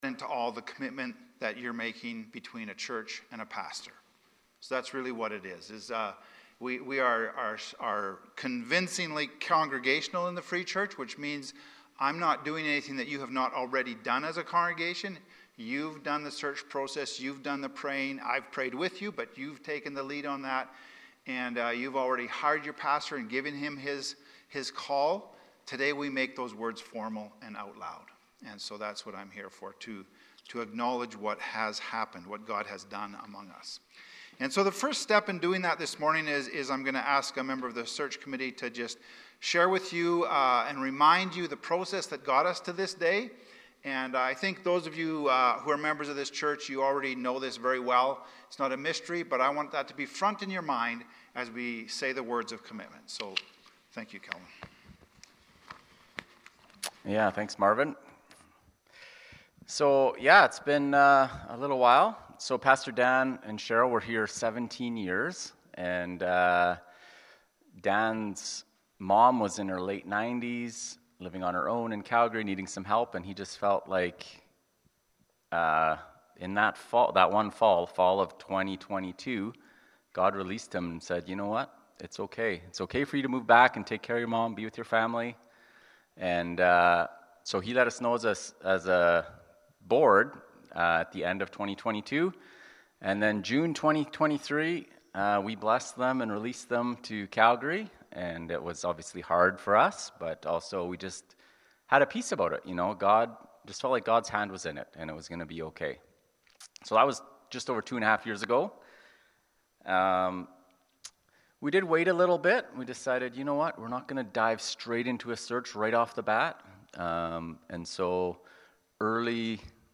Pastor Installation Service